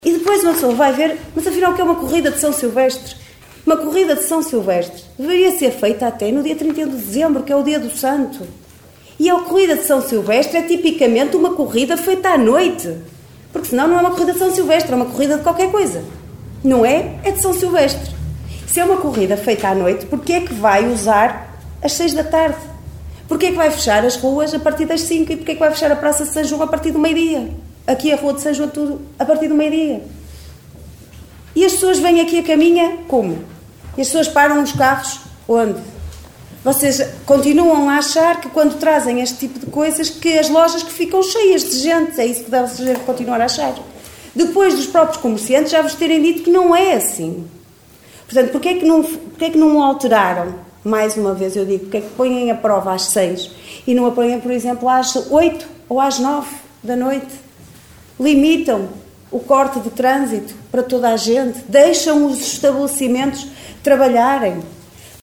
E para quem não sabe, a vereadora explicou o significado da corrida de S. Silvestre, lembrando que a mesma deveria ter lugar a 31 de dezembro que é o dia em que se celebra este santo, à noite e não às seis da tarde como acontece em Caminha.